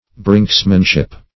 brinkmanship \brink"man*ship\, brinksmanship \brinks"man*ship\n.